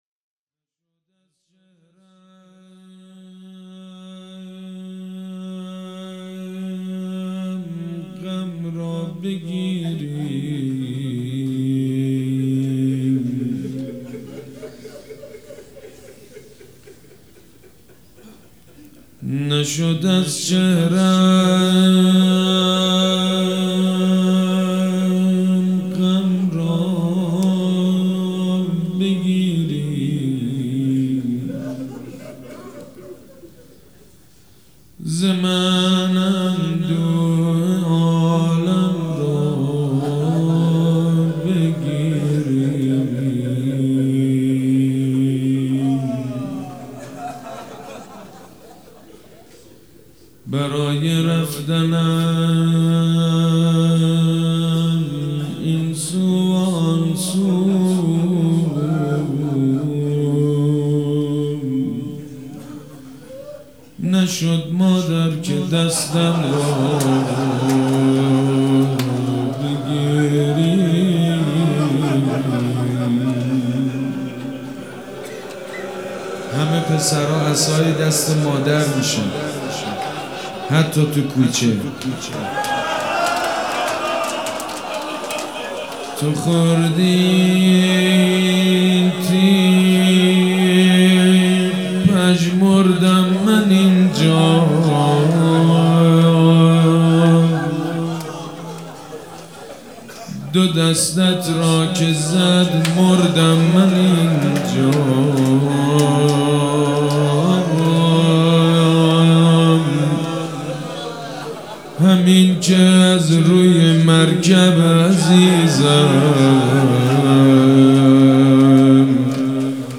روضه بخش اول
حاج سید مجید بنی فاطمه پنجشنبه 17 بهمن 1398 حسینیه ریحانة‌الحسین (س)
سبک اثــر روضه مداح حاج سید مجید بنی فاطمه